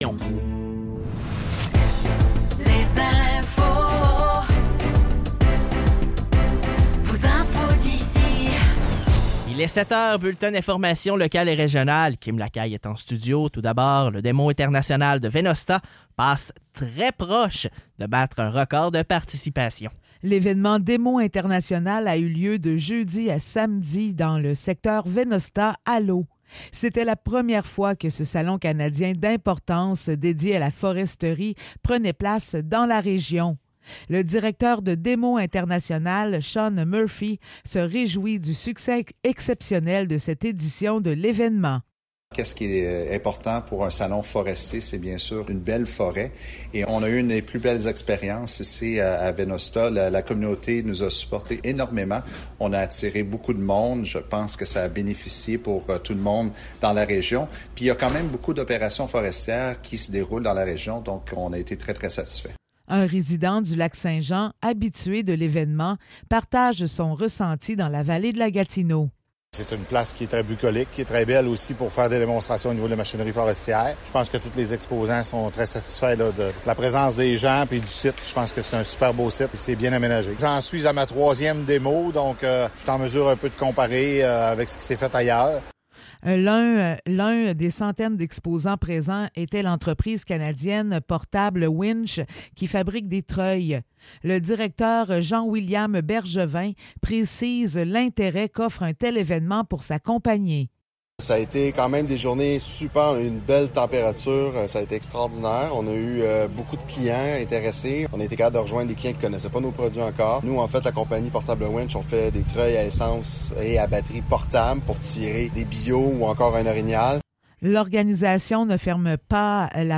Nouvelles locales - 23 septembre 2024 - 7 h